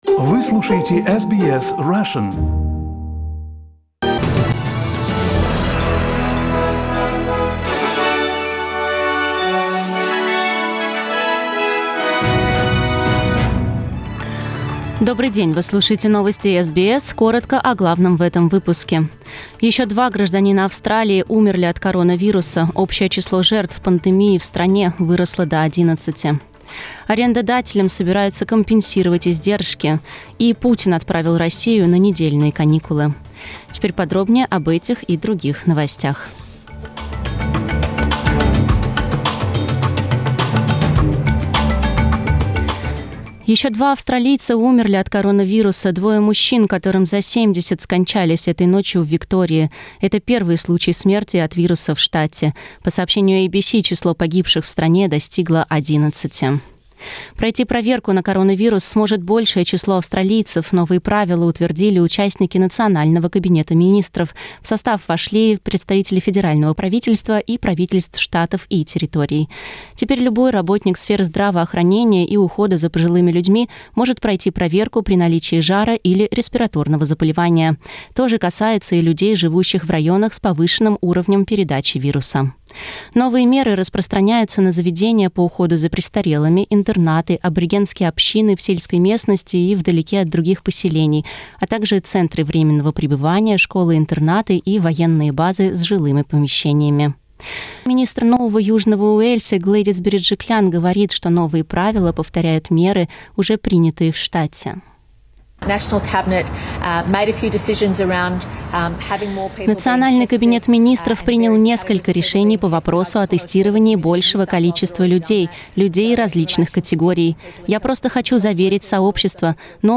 News bulletin 26th of March